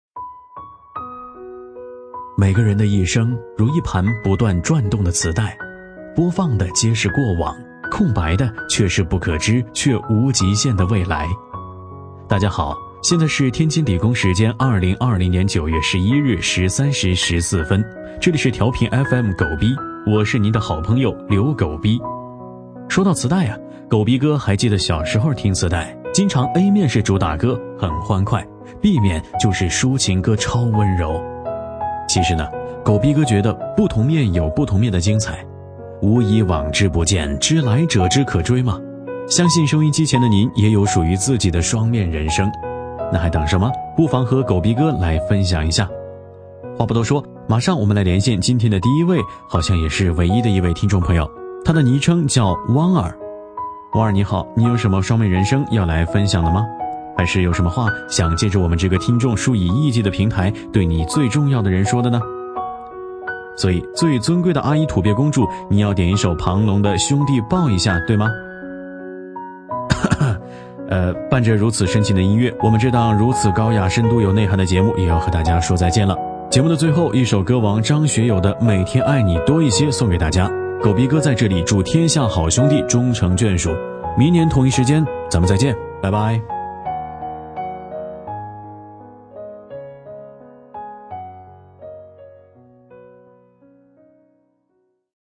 【男19号抒情电台】调频FM
【男19号抒情电台】调频FM.mp3